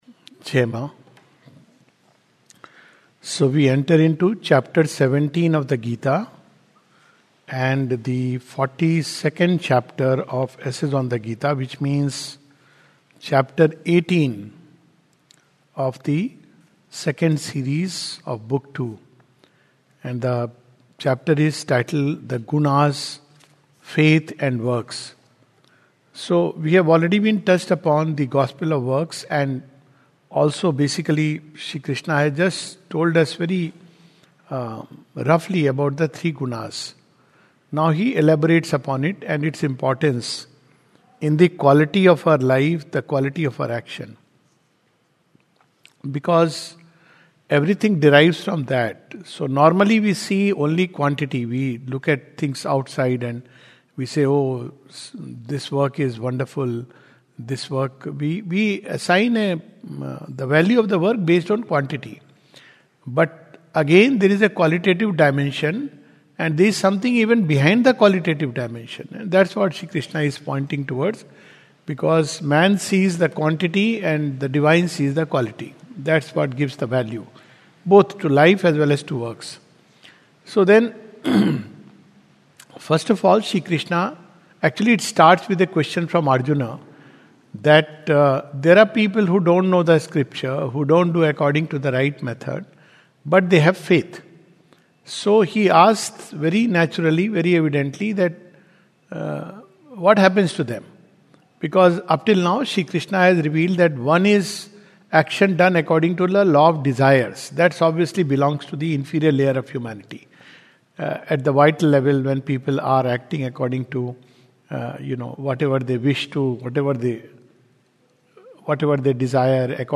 This is a summary of Chapter 18 of the Second Series of "Essays on the Gita" by Sri Aurobindo. A talk
recorded on 7th January, 2025 at the Savitri Bhavan, Auroville.